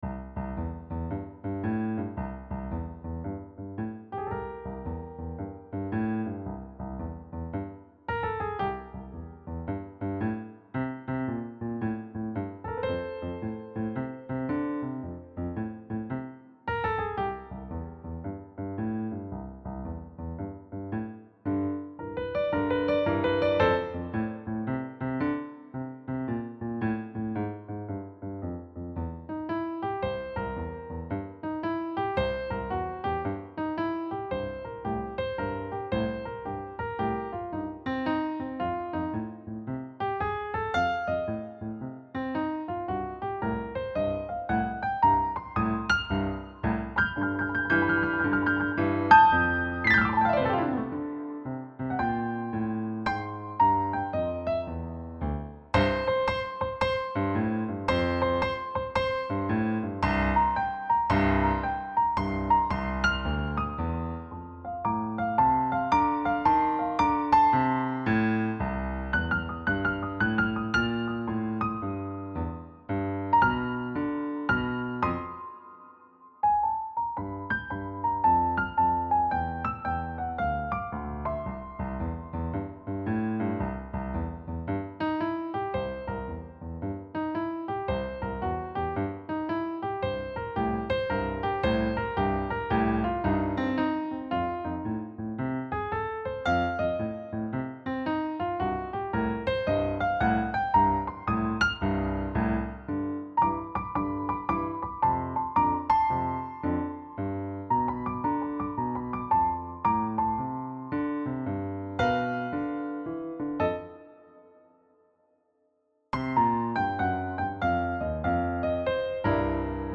Key: C Major
Time Signature: 4/4 (BPM ≈ 112)